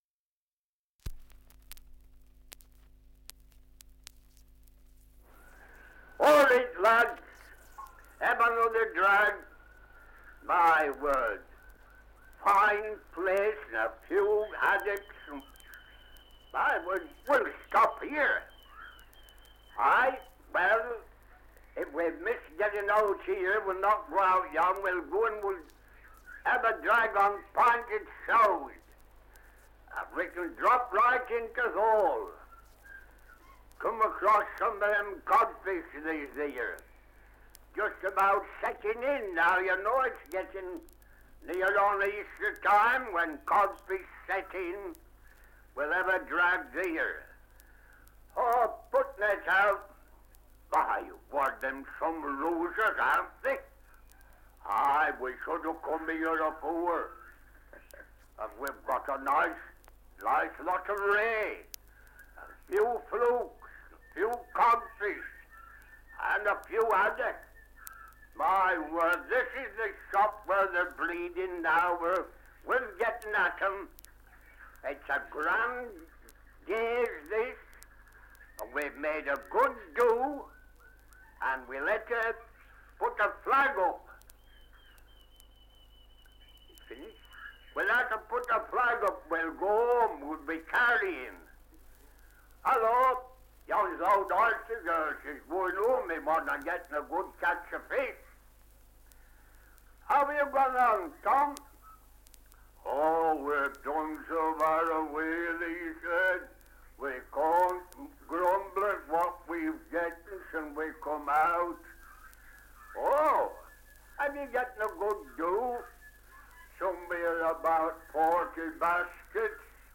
Survey of English Dialects recording in Fleetwood, Lancashire. Survey of English Dialects recording in Marshside, Lancashire
[Side 1] Dramatisation of trip to fishing grounds aboard 'Oyster Girl'.
78 r.p.m., cellulose nitrate on aluminium